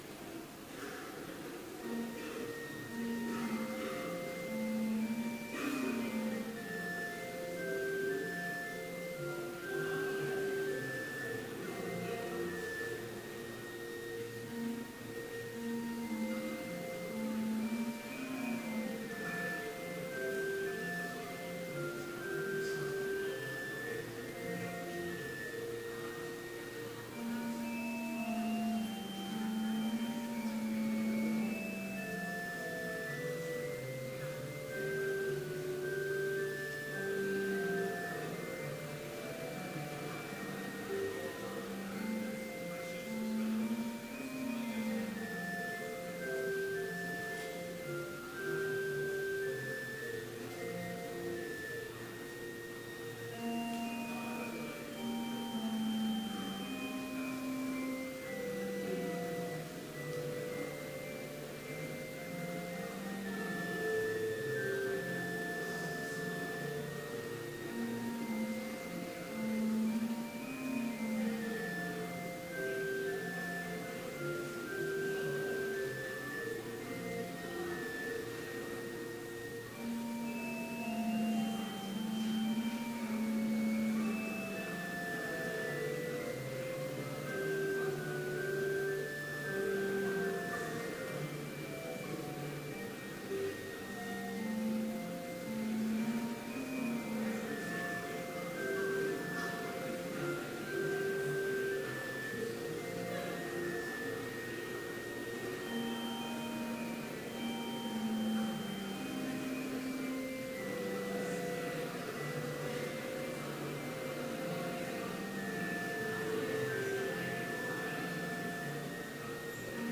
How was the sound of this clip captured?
Complete service audio for Chapel - October 12, 2016